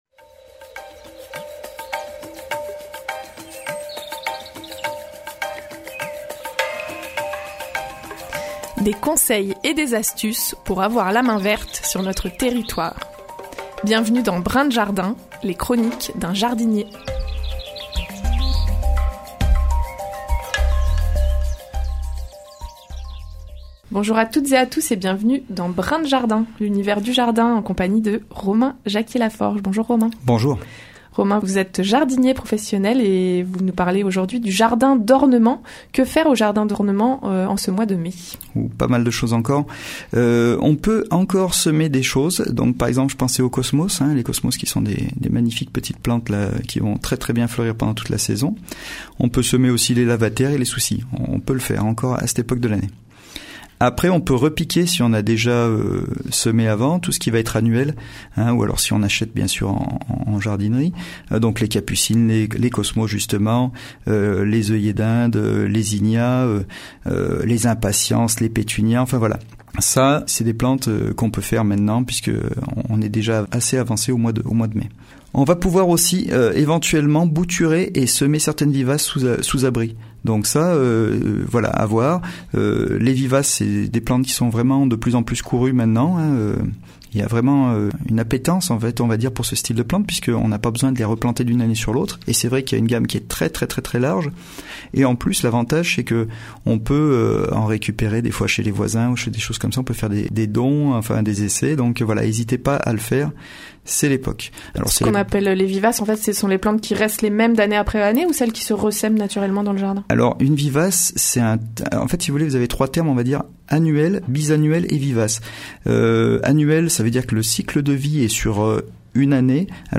La chronique jardin hebdomadaire sur les ondes de Radio Royans Vercors